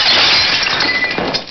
Glassbreak Die Of Death